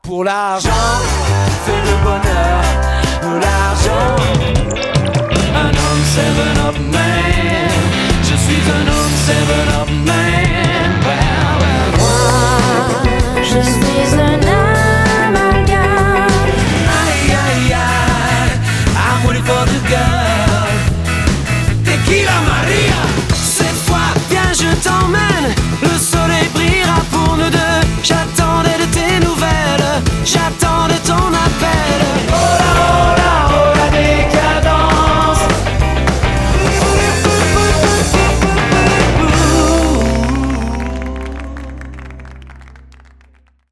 MONTAGE AUDIO